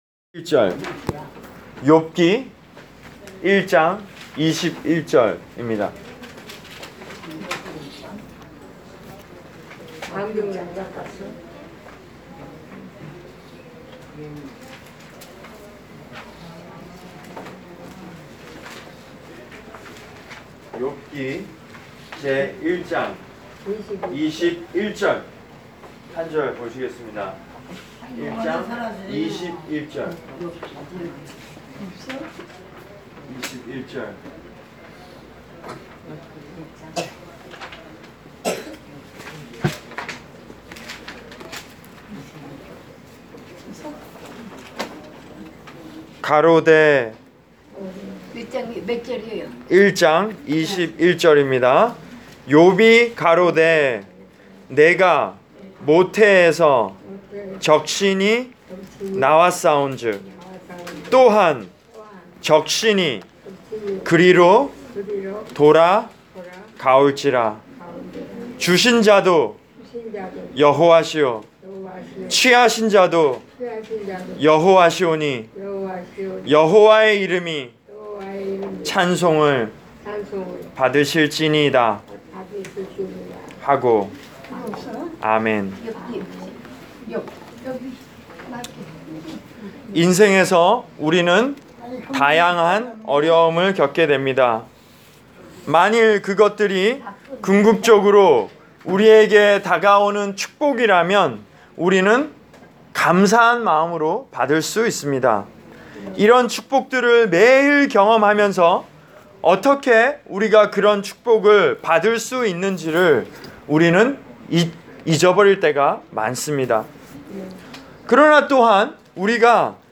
Preached for: Hudson View Rehab Center, North Bergen, N.J.